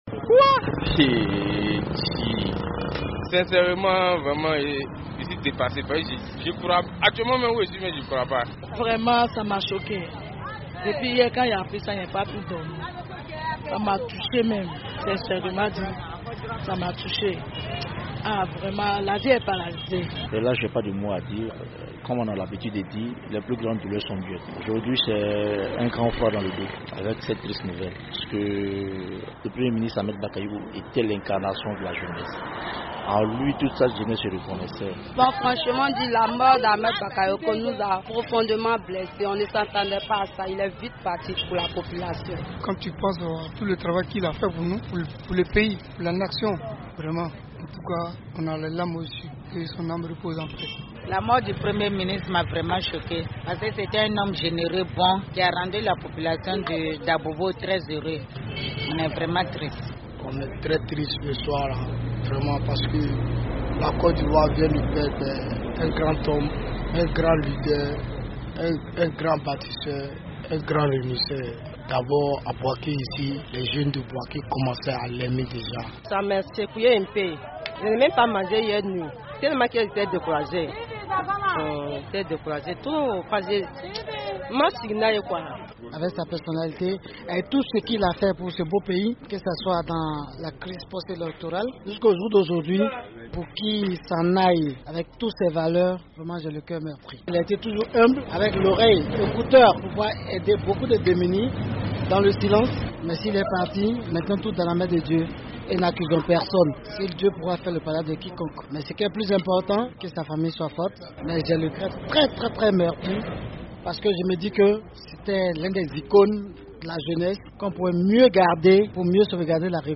Le premier ministre, ministre de la défense ivoirienne est décédé hier mercredi 10 mars 2021 des suites d’un cancer en Allemagne. Nous avons donc des réactions des populations ivoiriennes